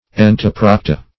Entoprocta \En`to*proc"ta\, n. pl. [NL., fr. Gr. ? within + ?